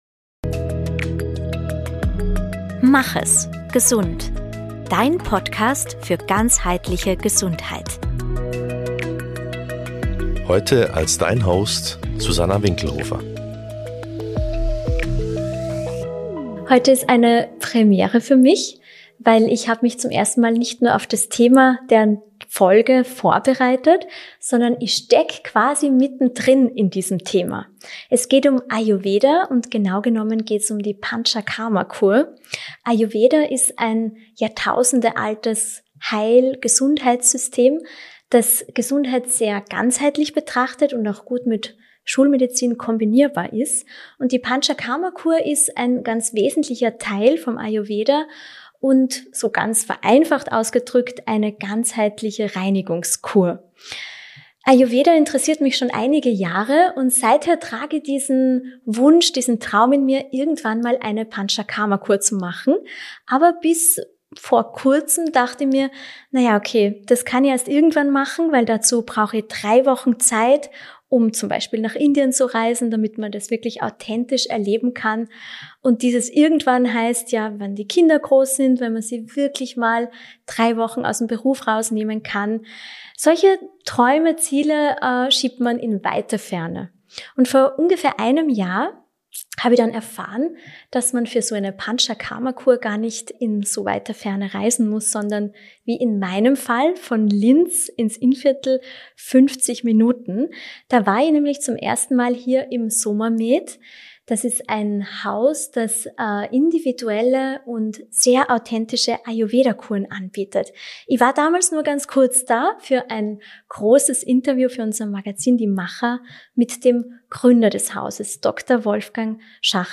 Nicht aus dem Studio. Sondern mittendrin.